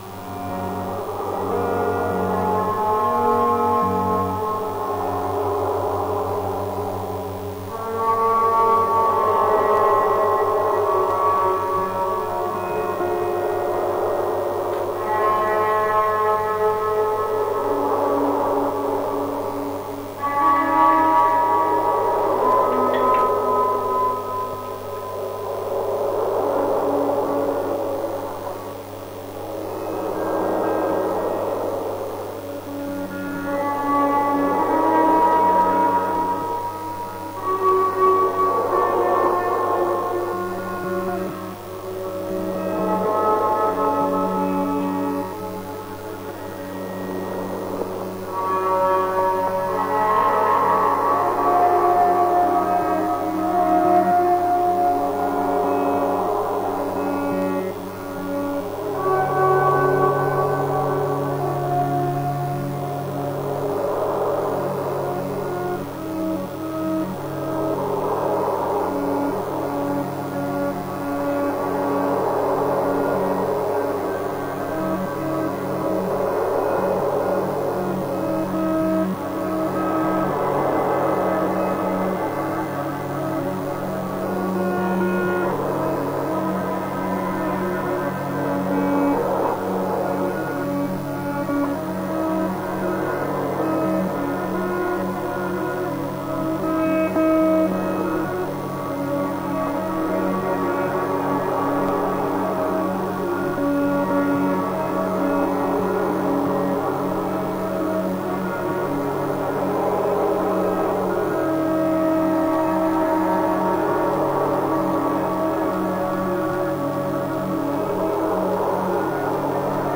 ※レコードの試聴はノイズが入ります。